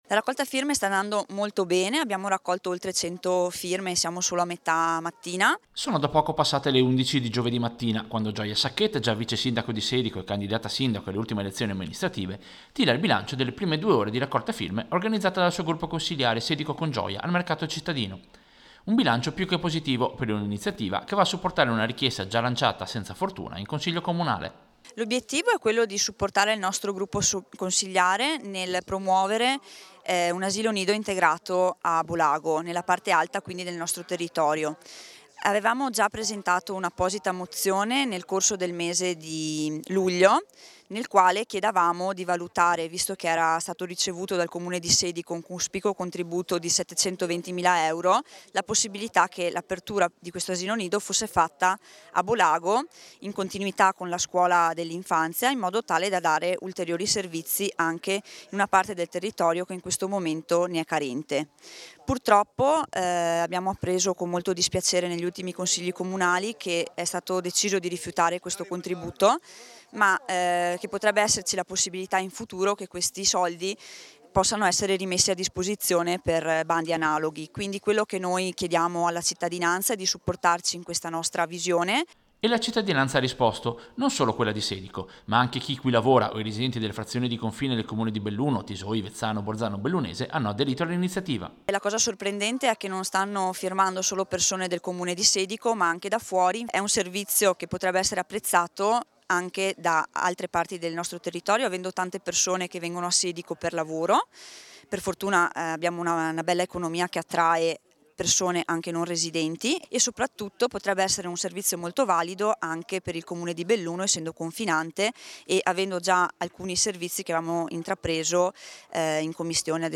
Servizio-Firme-asilo-Bolago.mp3